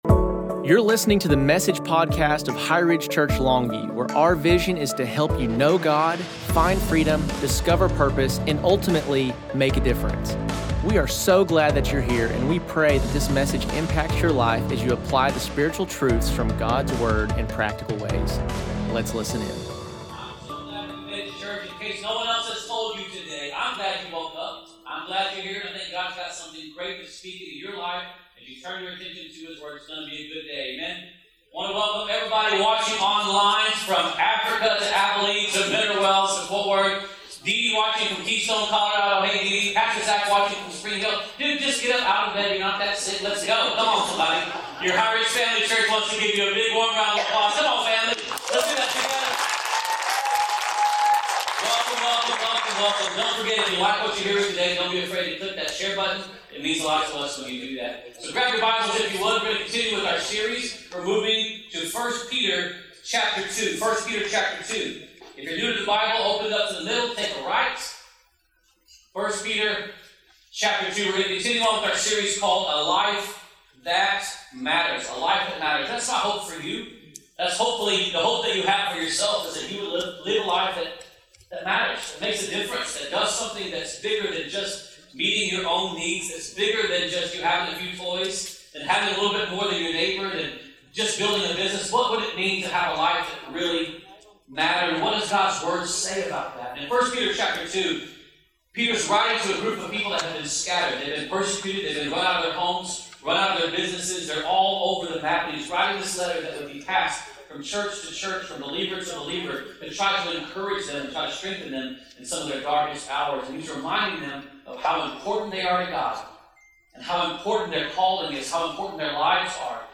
Message: A Life That Matters (Significant, Secure, and Shaped)